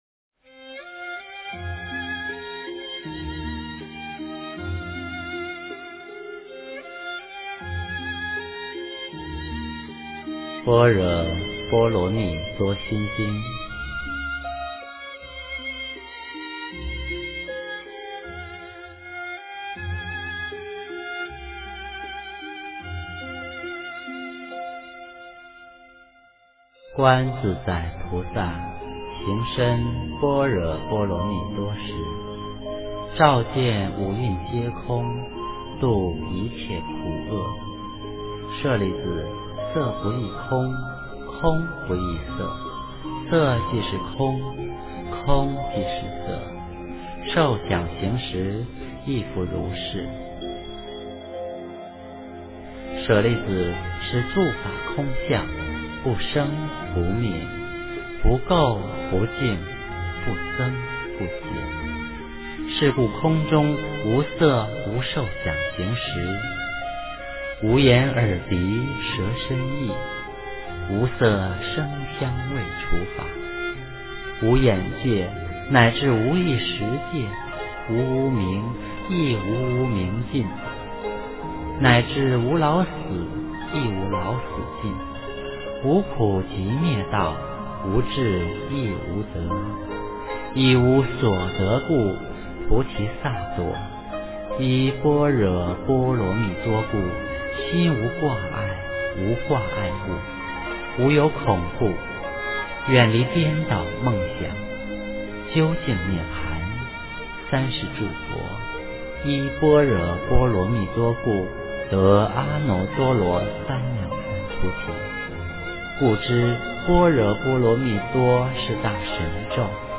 心经 - 诵经 - 云佛论坛
佛音 诵经 佛教音乐 返回列表 上一篇： 坛经（机缘品） 下一篇： 大般若波罗蜜多经402卷 相关文章 普门品--中国佛学院法师 普门品--中国佛学院法师...